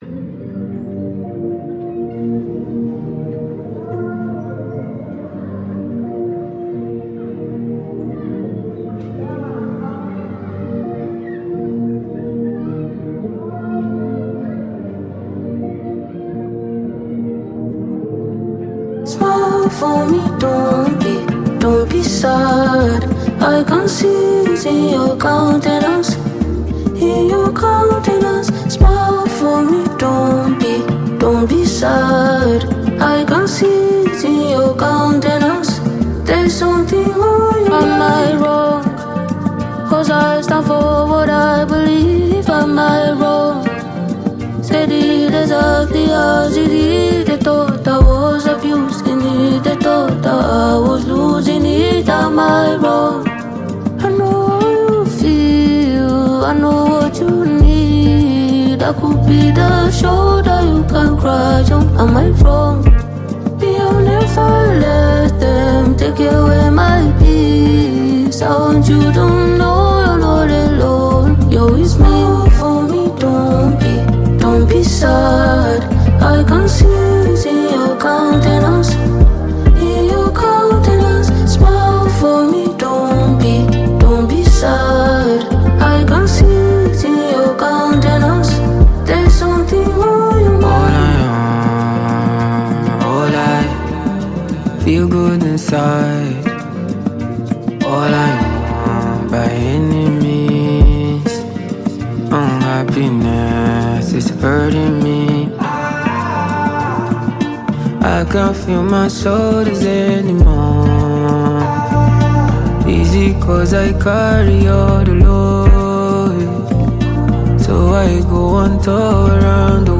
keeps his delivery smooth and honest